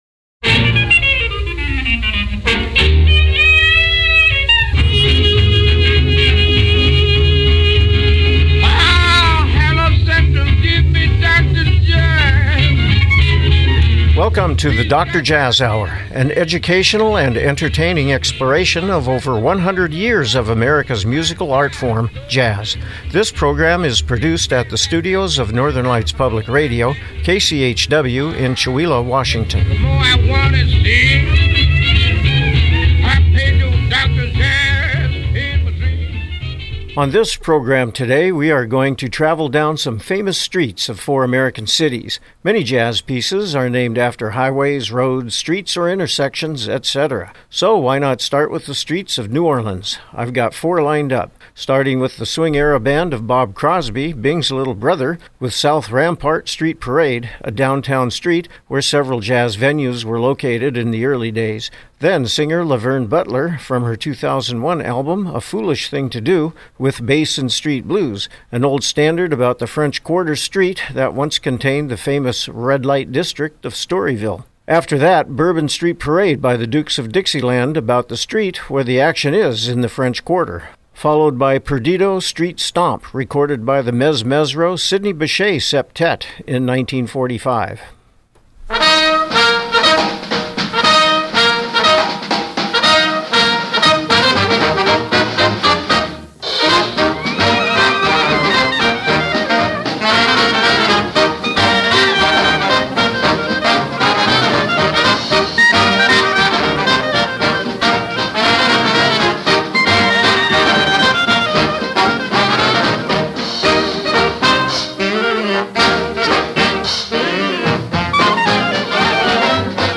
Program Type: Music